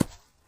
default_place_node_hard.2.ogg